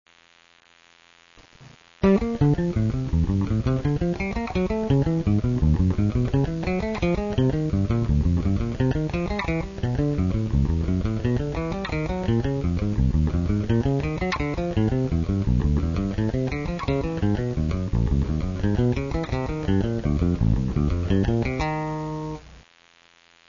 rythme à appliquer:  croche ou double croche avec un tempo donné par le métronome